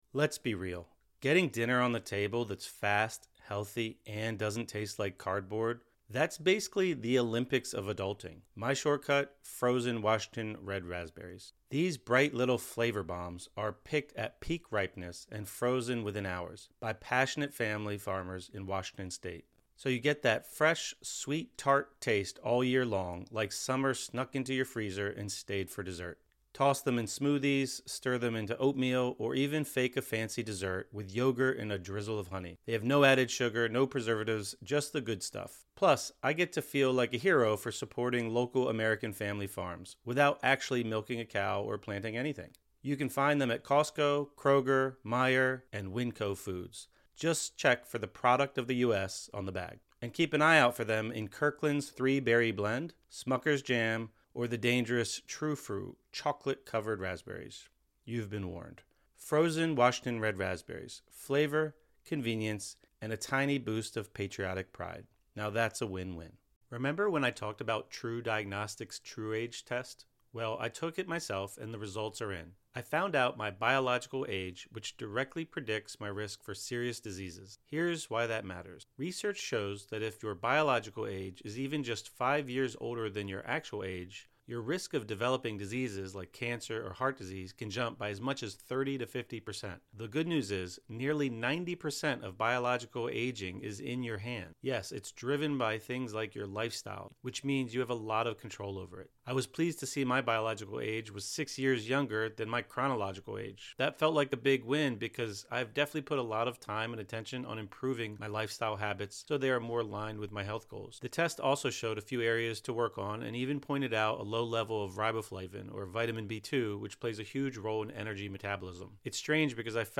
10 Minute Guided Meditation with Body Scan | Release Tension and Recharge Energy